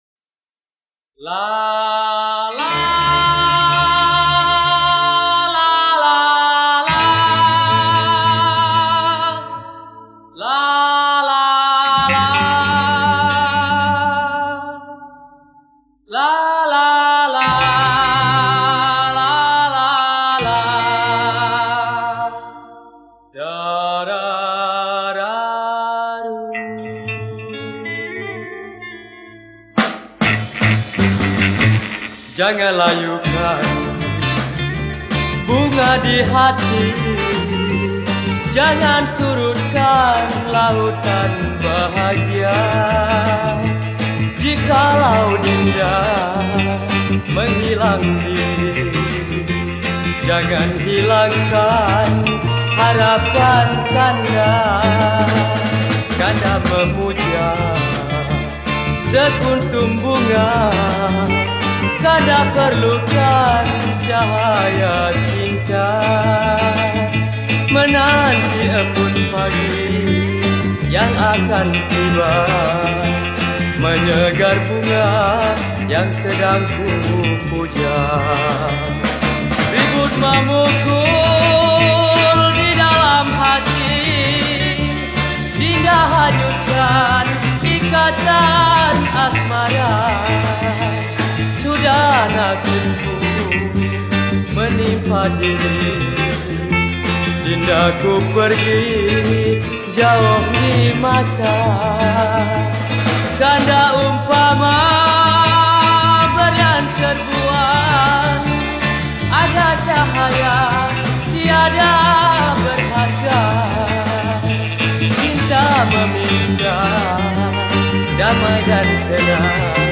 Pop Yeh Yeh
Skor Angklung